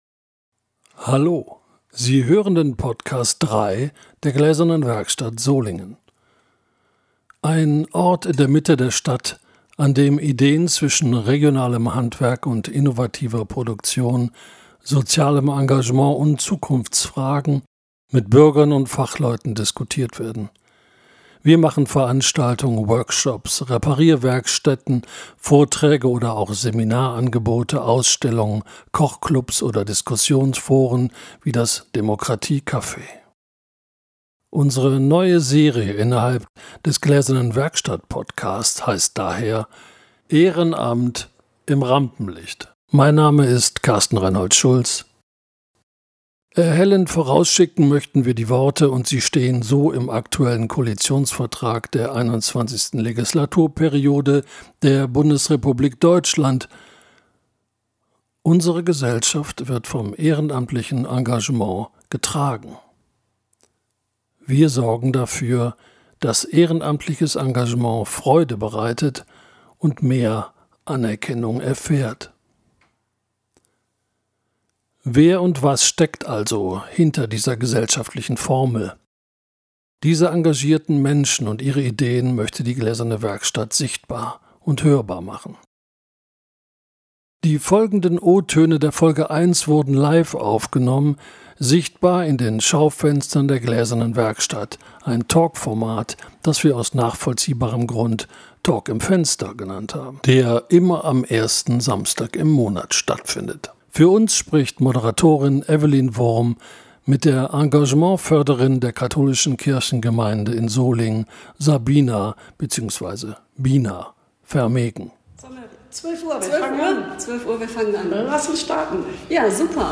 Jeden ersten Samstag im Monat Talk im Schaufenster der Gläsernen Werkstatt Solingen: Ein Plädoyer für's Ehrenamt.